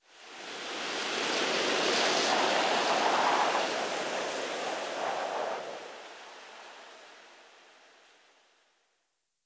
windwhistle1.ogg